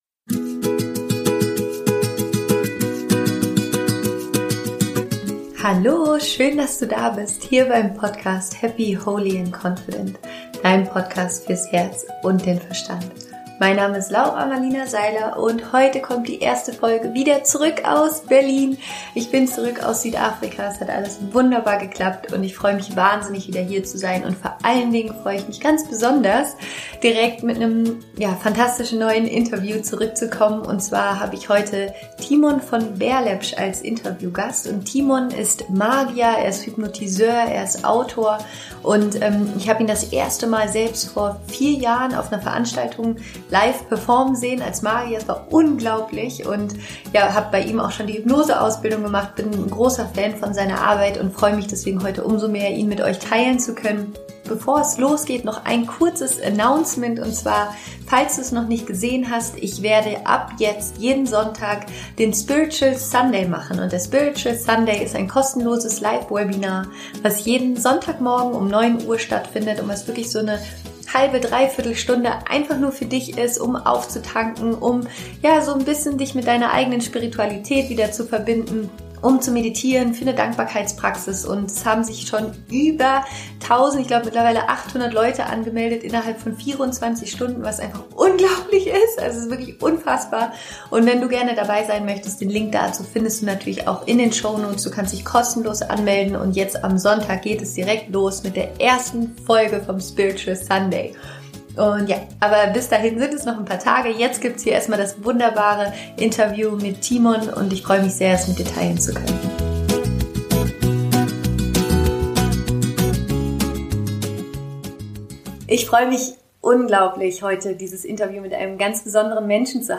Wie du die Magie des Lebens neu entdeckst - Interview mit Thimon von Berlepsch